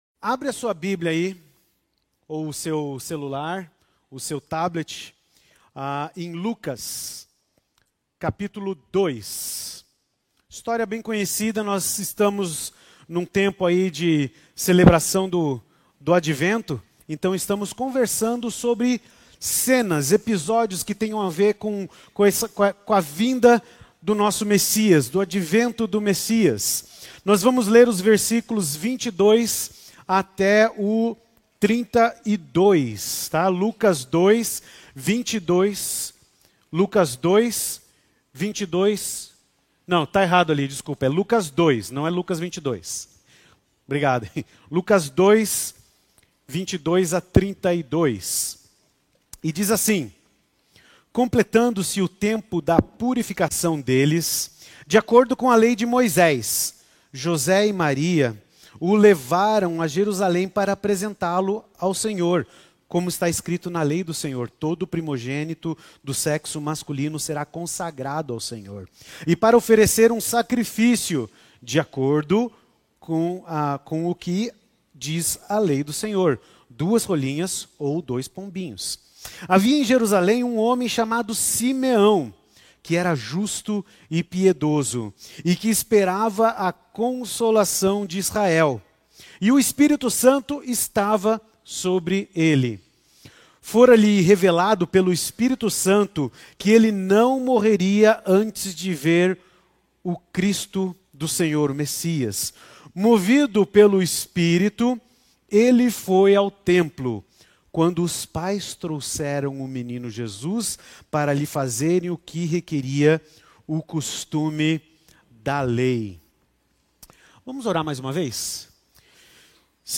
Mensagem
na Igreja Batista do Bacacheri.